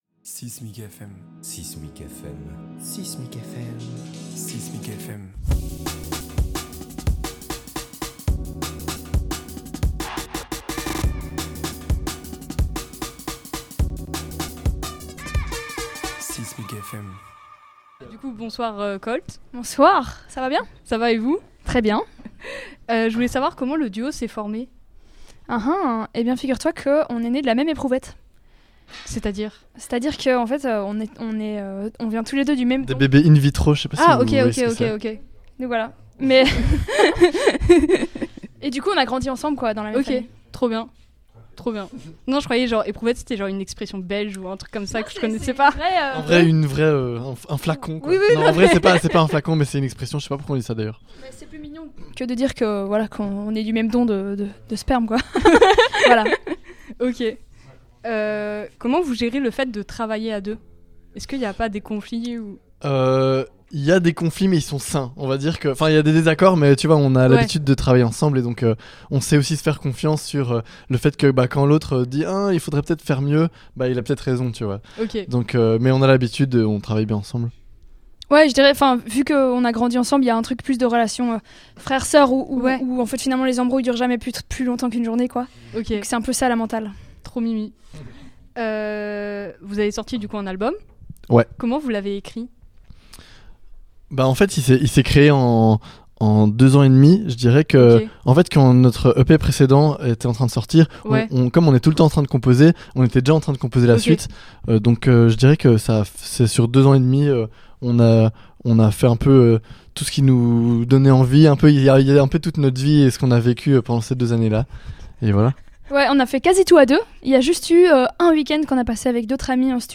6mic FM est une nouvelle collaboration entre 6mic et RadioZai où les musiciens discutent avec nous dans ce lieu emblématique d'Aix-en-Provence.